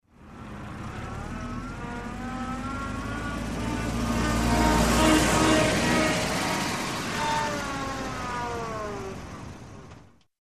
Шум реверса грузового автомобиля Газель